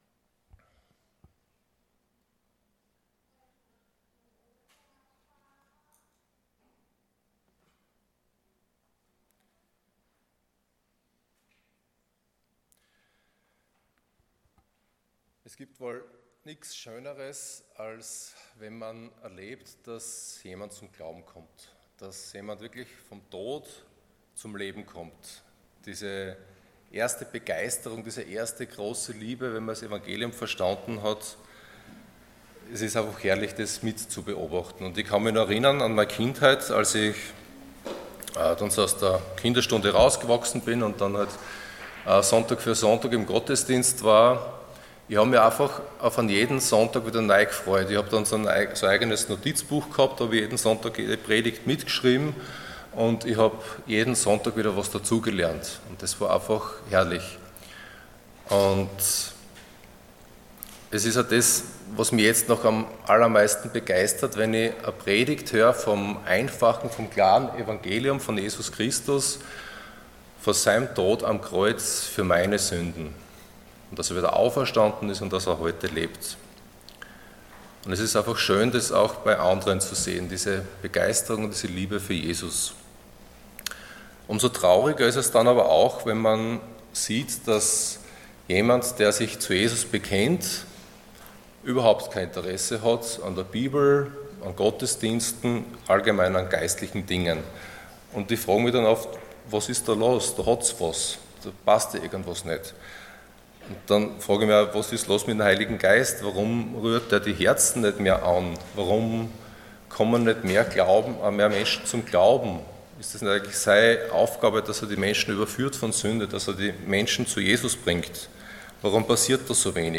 Passage: Ezekiel 36:16-38 Dienstart: Sonntag Morgen